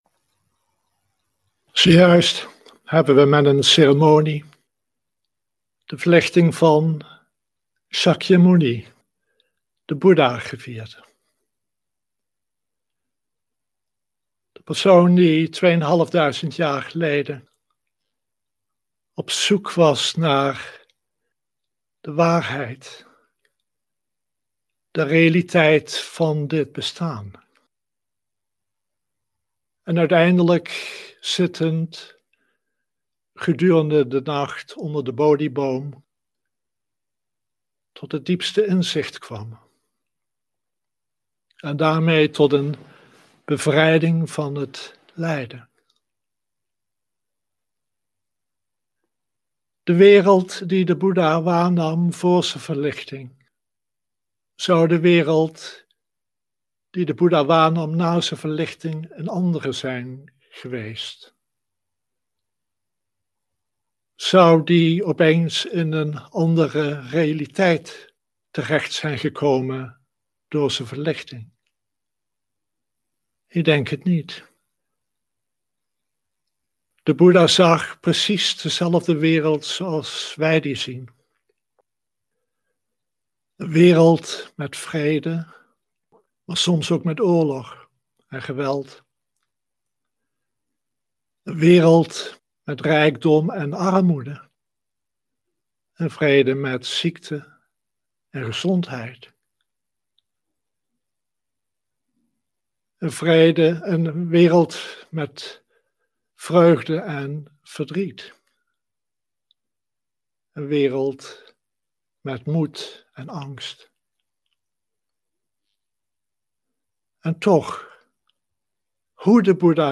Dharma-onderwijs
Livestream opname
Gegeven na de ceremonie van de Boeddha's verlichting.